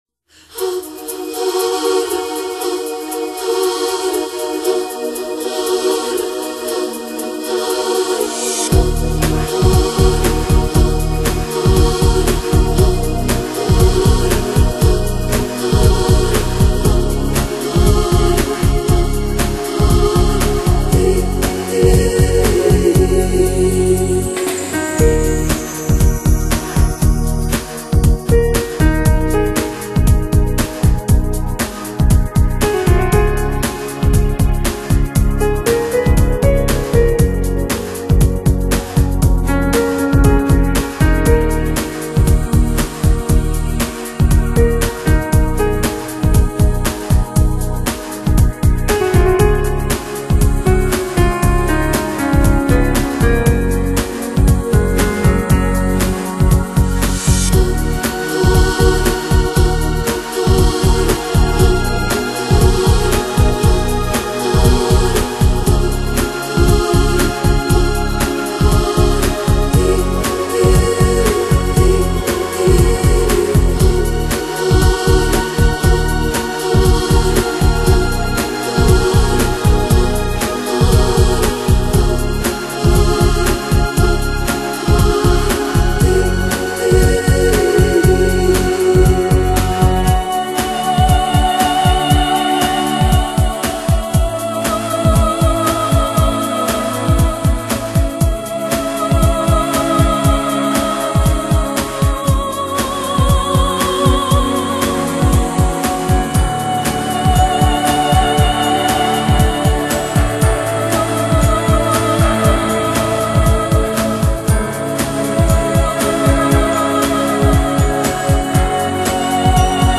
同时大量加入电子音效。
柔美浪漫的女声、沁人心脾的节奏，听起来是如此的心旷神怡。
新世纪音乐的减压功能，在该碟中得到完美的体现！